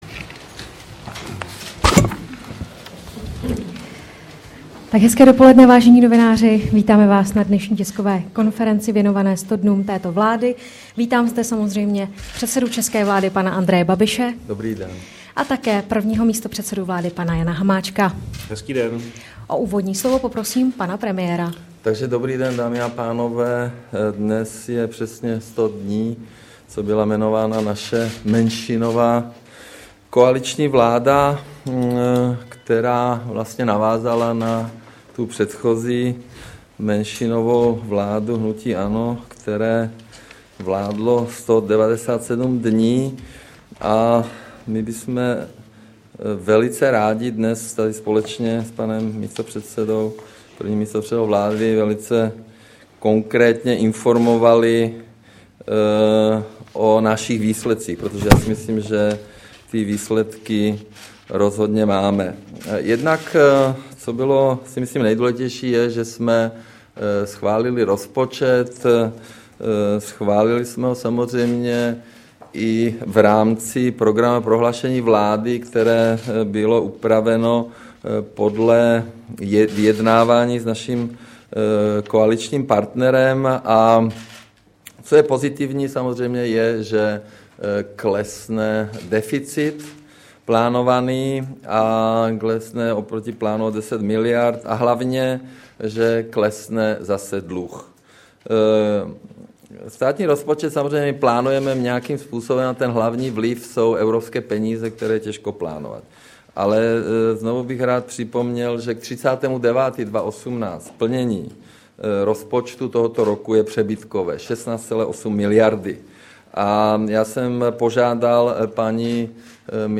Tisková konference ke 100 dnům vlády, 4. října 2018